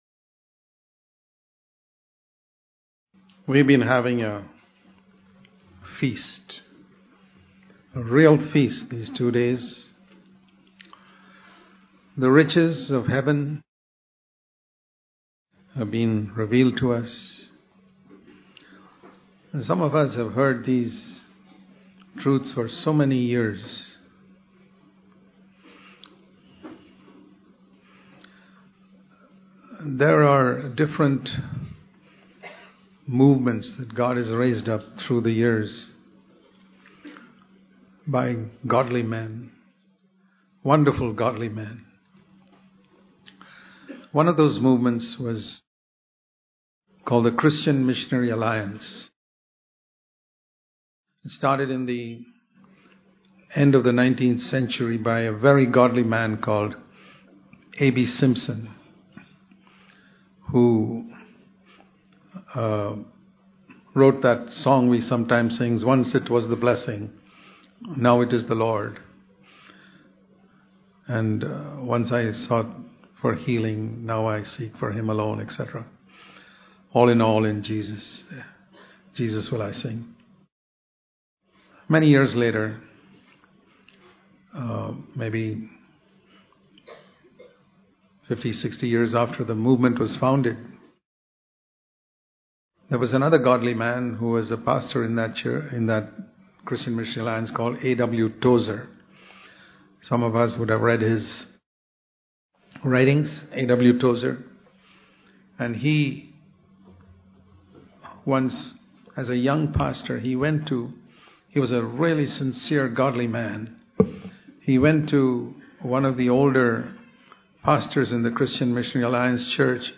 Knowing The Father And Jesus Preserving The Glory of The Lord in Our Church Bangalore Conference - 2015 Date: 22nd - 25th October, 2015 Place: Bangalore Click here to View All Sermons